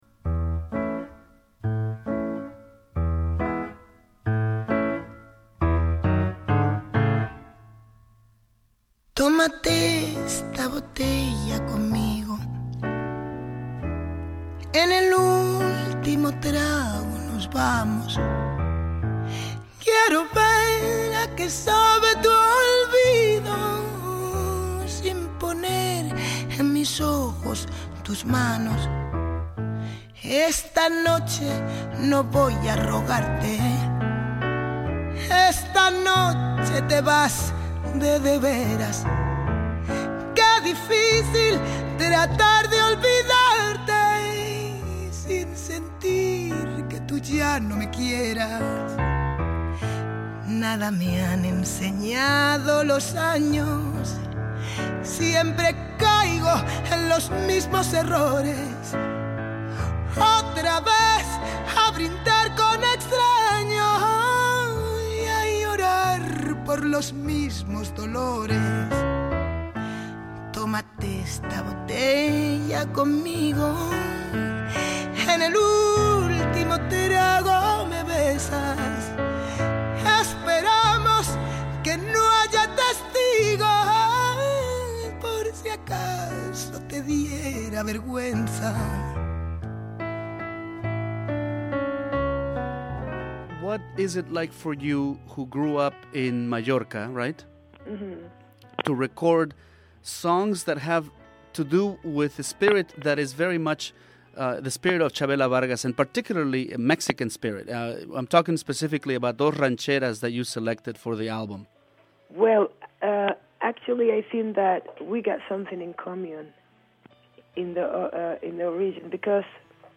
Interview with Buika